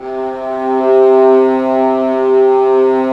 Index of /90_sSampleCDs/Roland LCDP13 String Sections/STR_Violas FX/STR_Vas Sordino